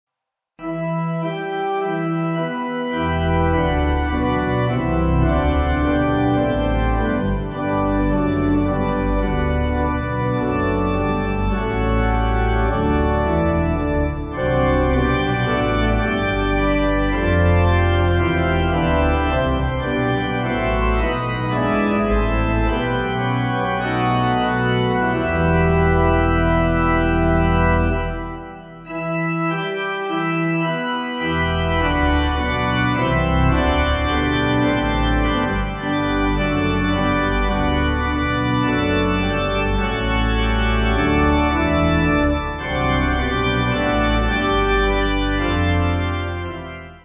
Organ
(CM)   4/Em